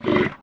animalia_reindeer_hurt.ogg